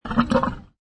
Camel 9 Sound Effect Free Download